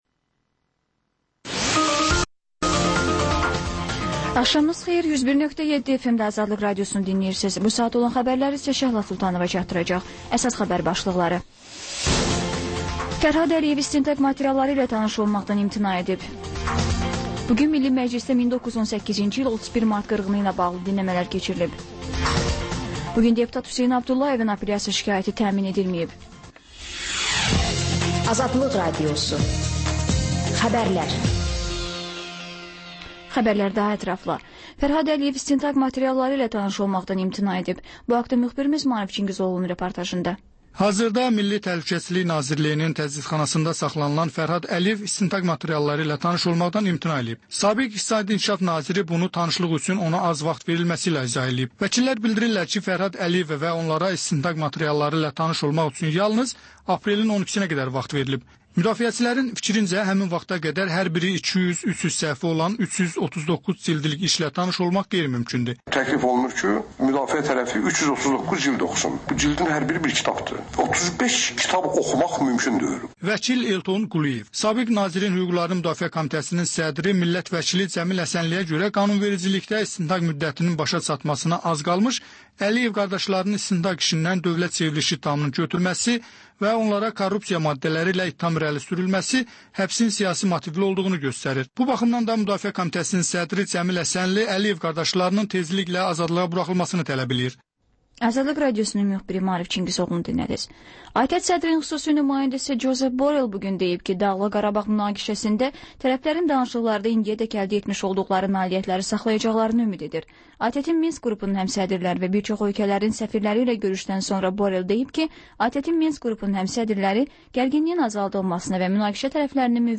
Xəbərlər, müsahibələr, hadisələrin müzakirəsi, təhlillər, sonda XÜSUSİ REPORTAJ rubrikası: Ölkənin ictimai-siyasi həyatına dair müxbir araşdırmaları